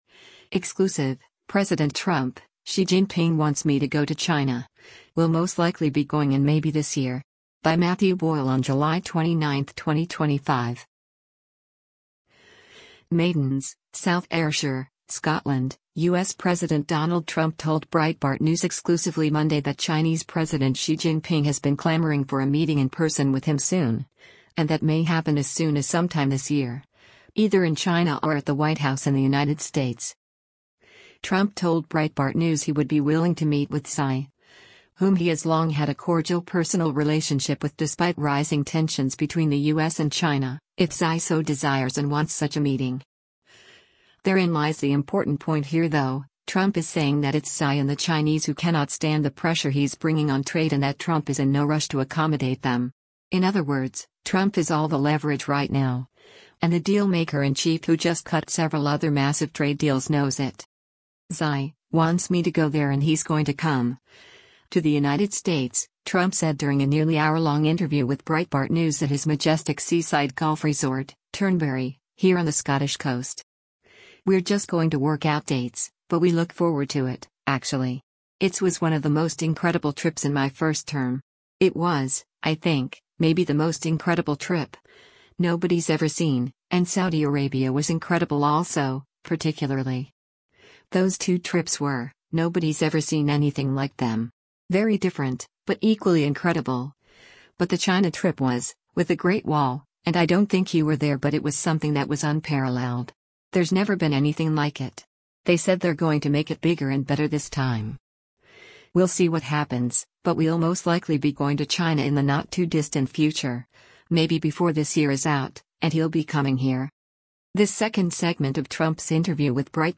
“[Xi] wants me to go there and he’s going to come [to the United States],” Trump said during a nearly hourlong interview with Breitbart News at his majestic seaside golf resort, Turnberry, here on the Scottish coast.
This interview was taped with Trump right before the arrival of British Prime Minister Keir Starmer to Turnberry for a bilateral meeting and press availability with the two world leaders.